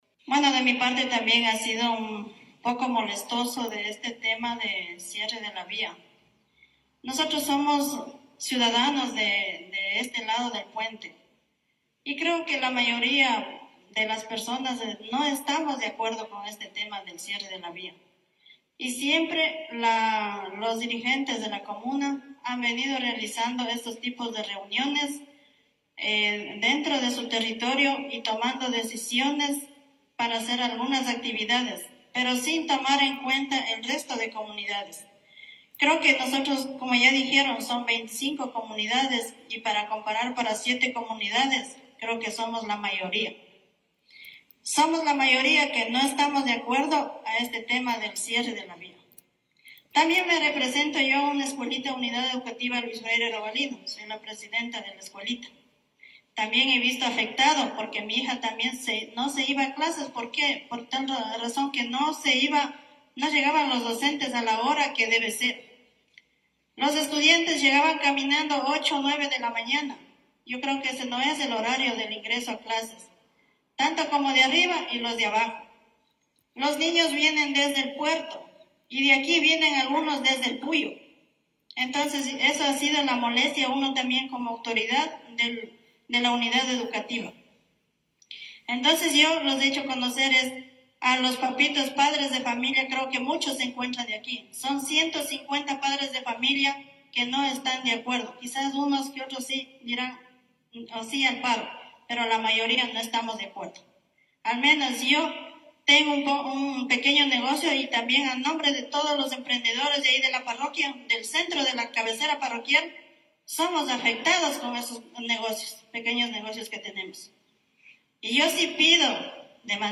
Docente de Canelos, denunció que los niños ya pierden una semana de clases.